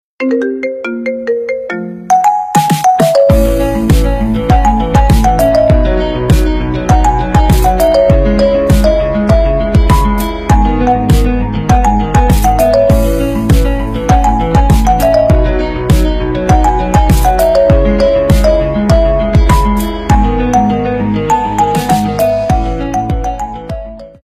Marimba Remix